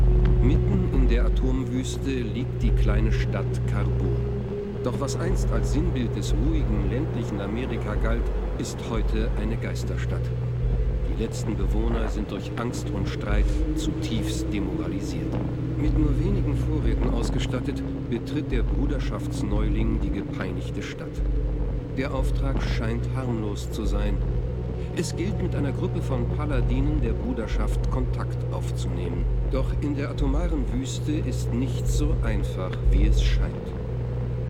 Kategorie:Fallout: Brotherhood of Steel: Audiodialoge Du kannst diese Datei nicht überschreiben.